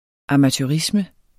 Udtale [ amatøˈʁismə ]